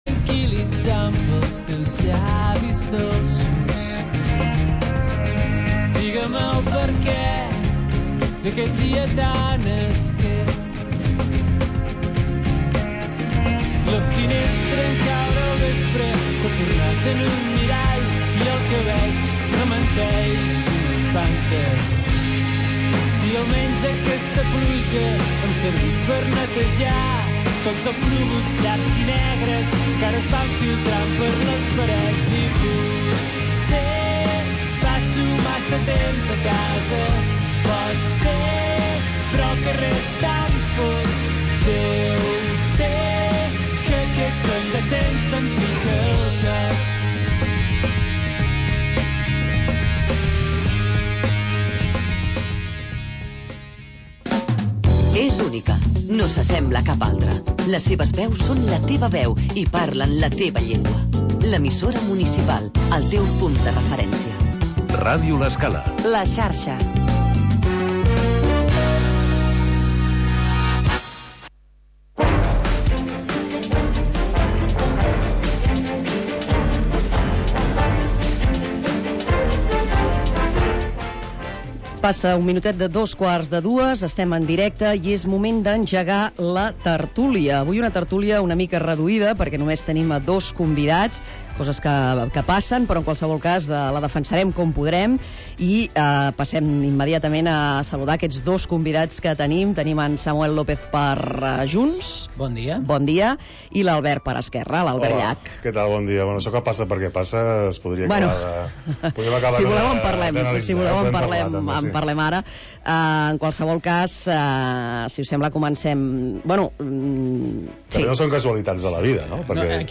Tertúlia de caire polític